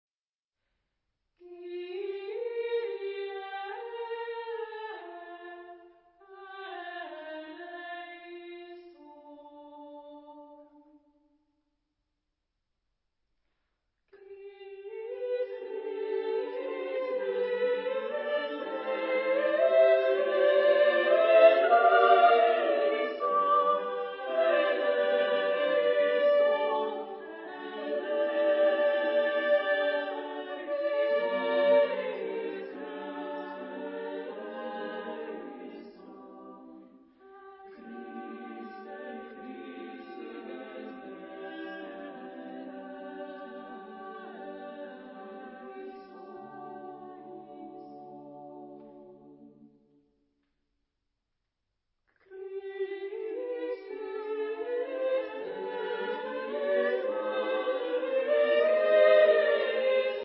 Genre-Style-Form: Mass ; Sacred
Type of Choir: SSSAA + div.  (5 equal voices )
Source of text: There are quotations of Gregorian chants.